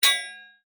Sword Hit B.wav